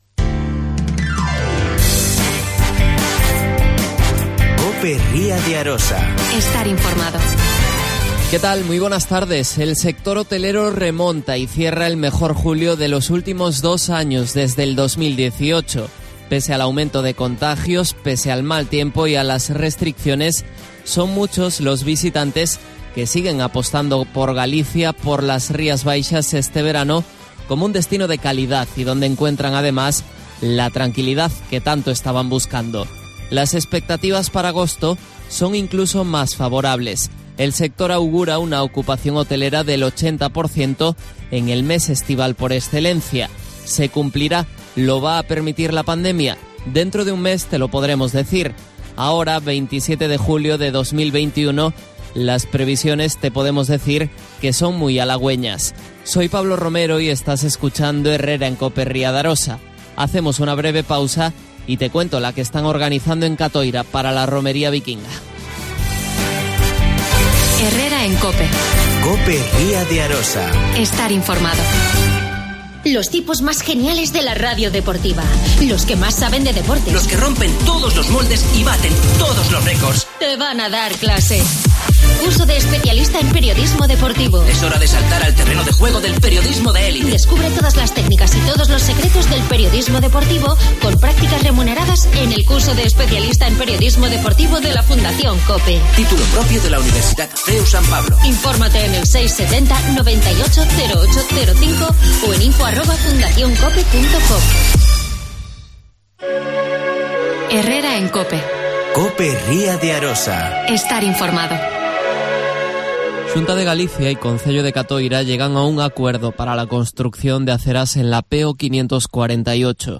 Portavoz del PP en Cotoira.